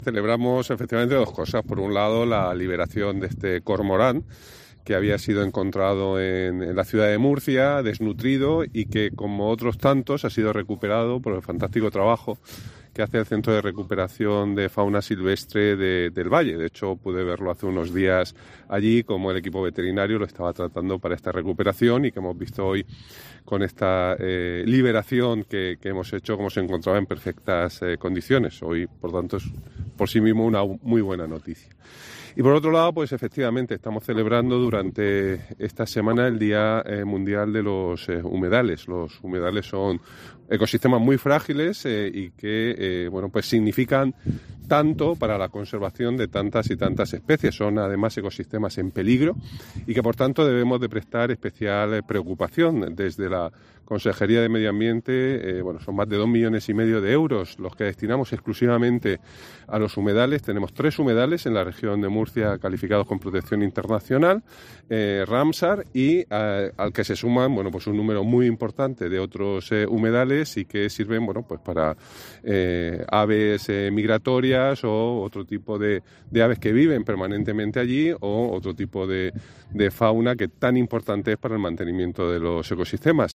Juan María Vázquez, consejero de Medio Ambiente, Mar Menor, Universidades e Investigación